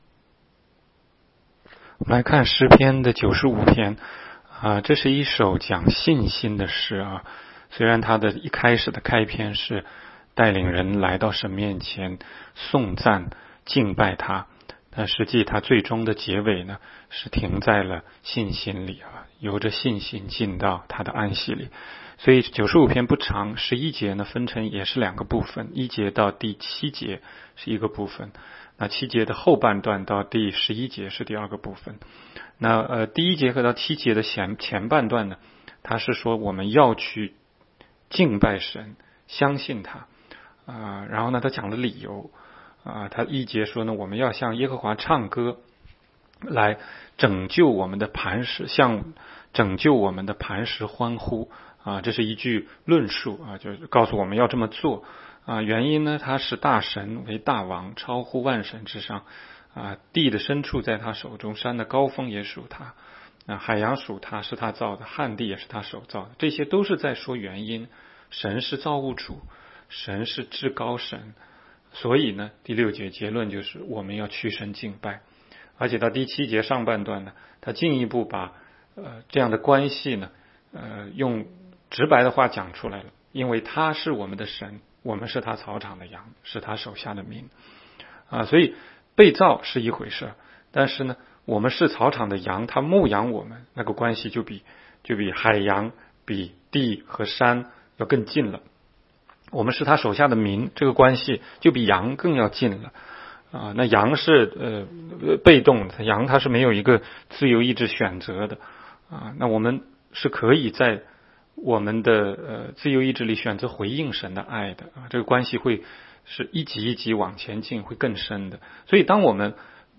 16街讲道录音 - 每日读经-《诗篇》95章